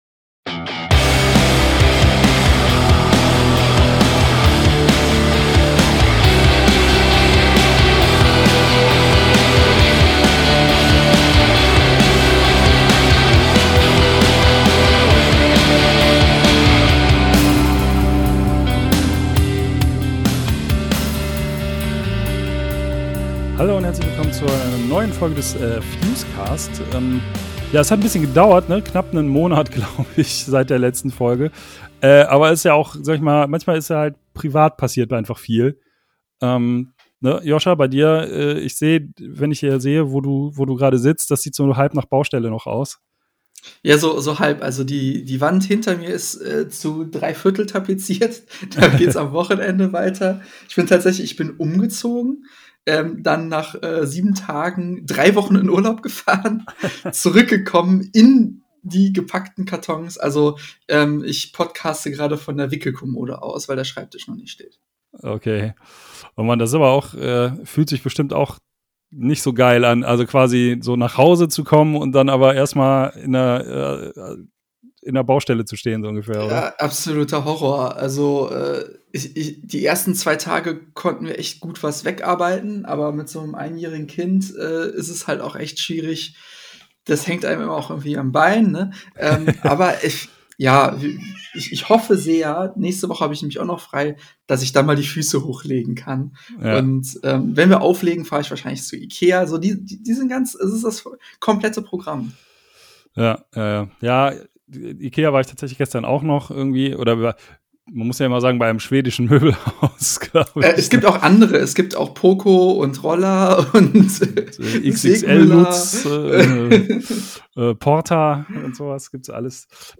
Im Interview hört noch INDECENT BEHAVIOR mit denen wir über das Saarland und einige andere Dinge gesprochen haben.